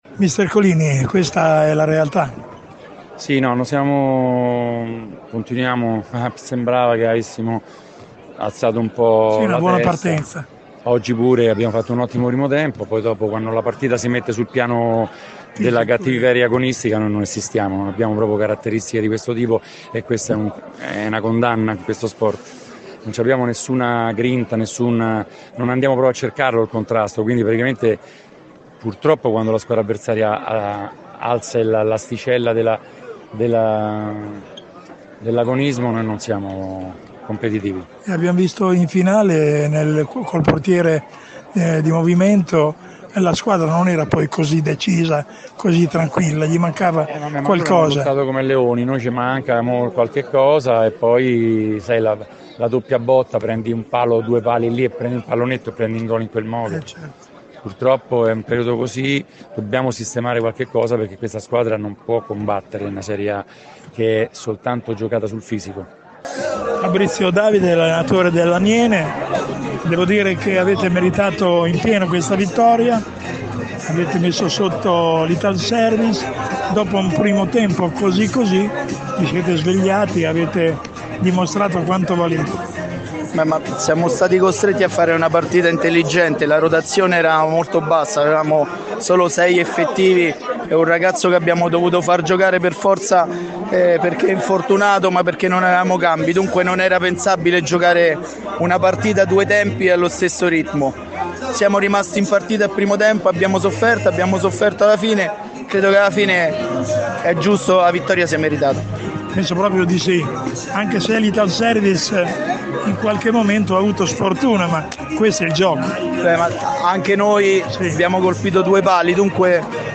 Le interviste post partita